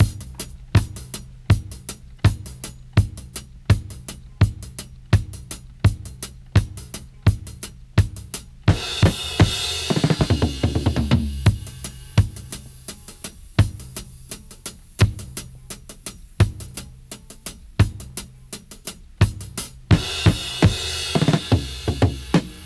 85-DRY-01.wav